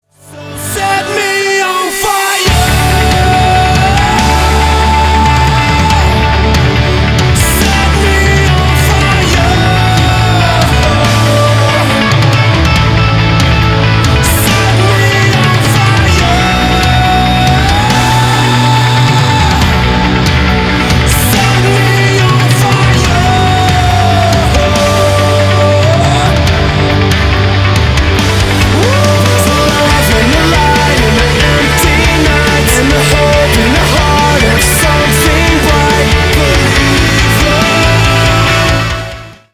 • Качество: 320, Stereo
мужской вокал
громкие
мощные
Metalcore
Post-Hardcore